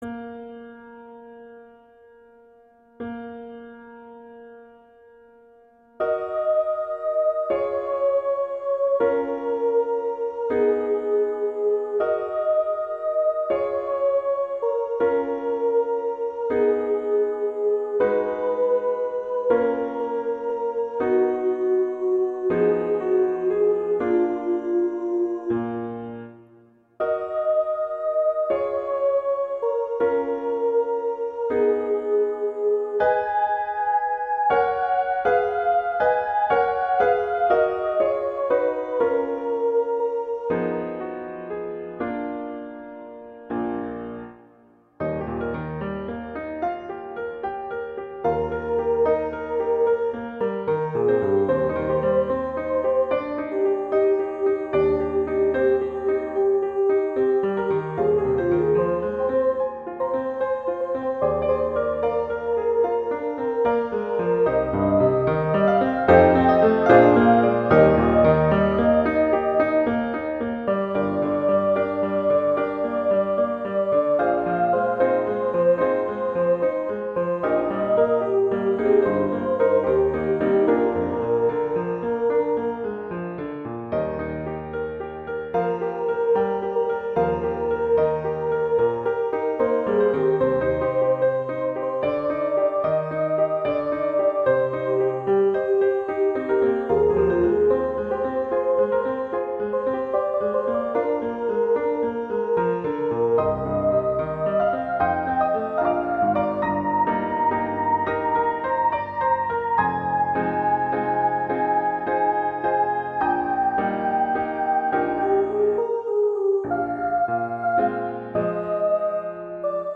opera, classical